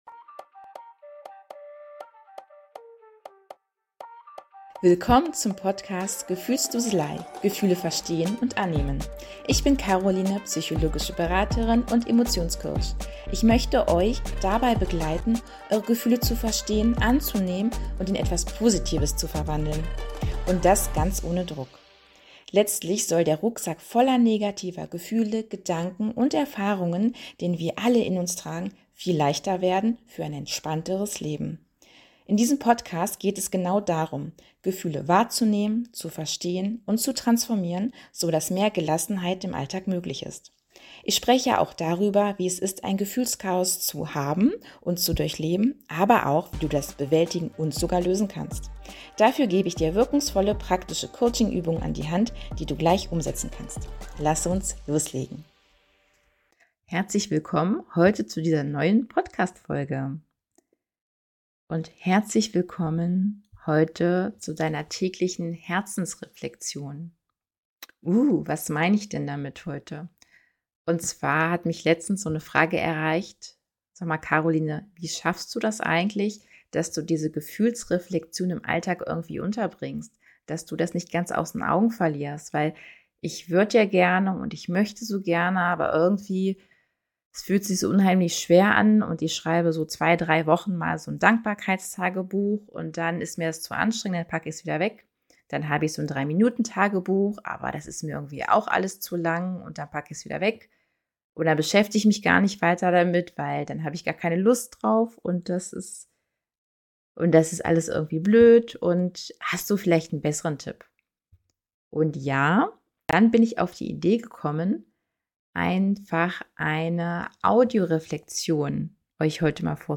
Sanfte Fragen: liebevoll formulierte Fragen, die dich einladen, innezuhalten und deine Gefühle zu erkunden.